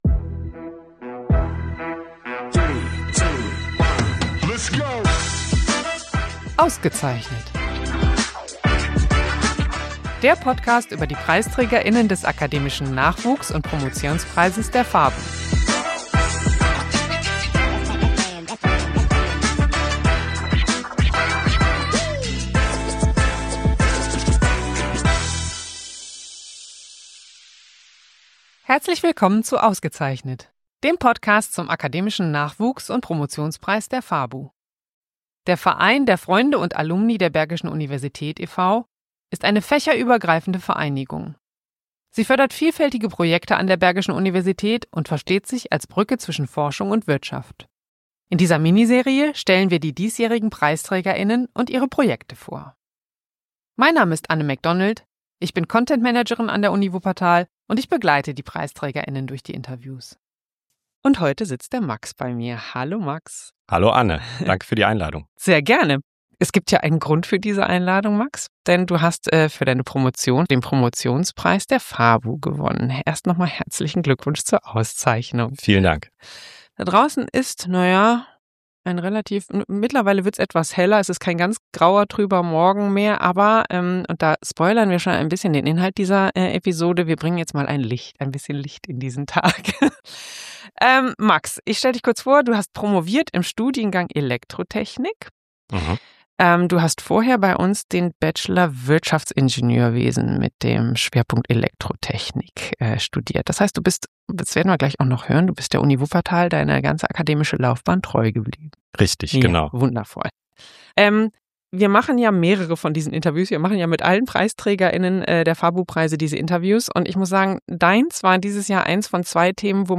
Also: Akku laden und reinhören. zur Folge Der Photonenbändiger und die perfekte Welle Wie sagt man Licht, wo es lang geht? Im Interview